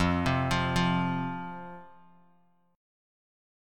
F5 Chord
Listen to F5 strummed